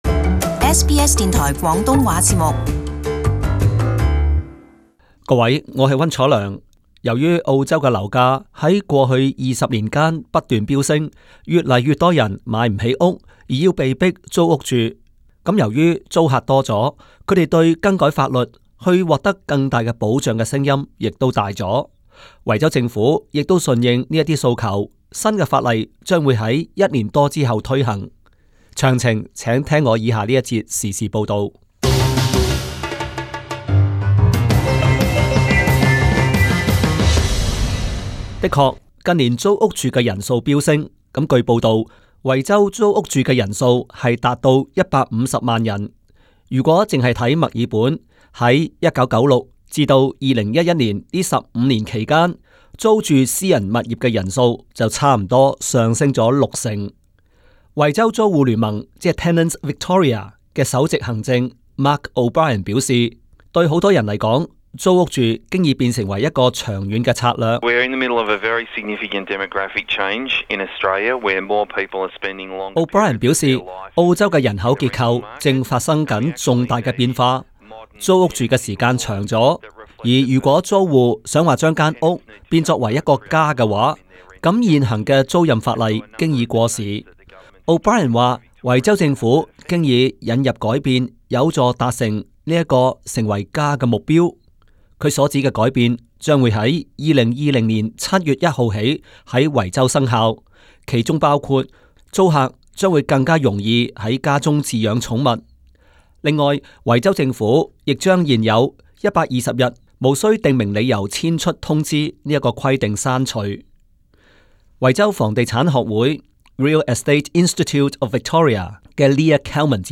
【時事報導】 2020 年維州推行新租務法例